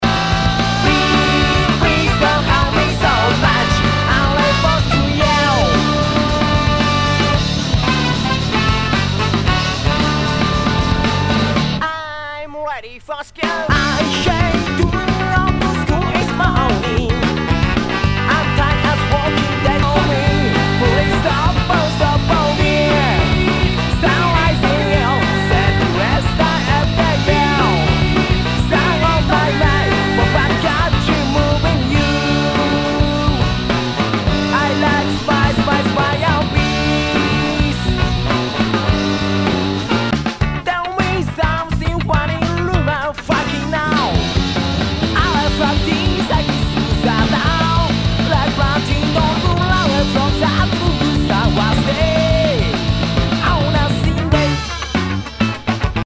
和モノ/JAPANEASE GROOVE
ナイス！スカ / パンク！